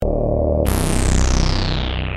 Sequencial Circuits - Prophet 600 50